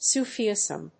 音節Su・fi・ism 発音記号・読み方
/súːfiìzm(米国英語)/